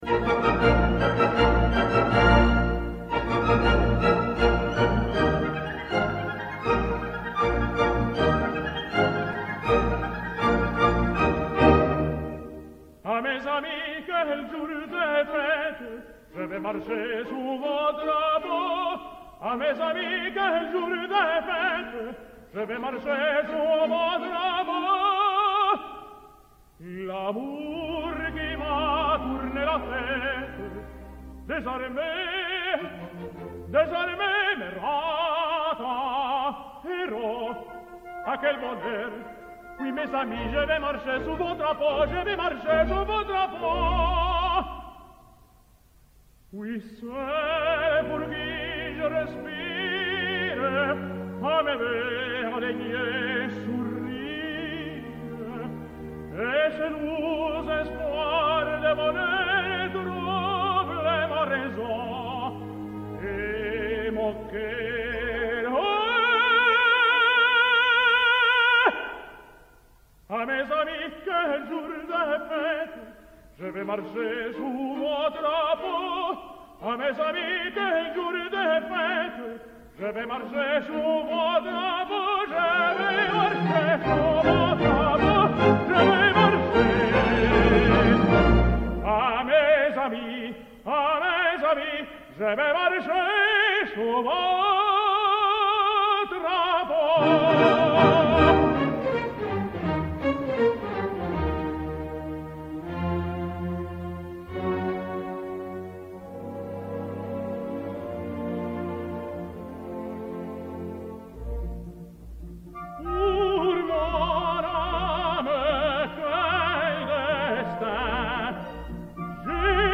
Fernando de la Mora sings La fille du régiment: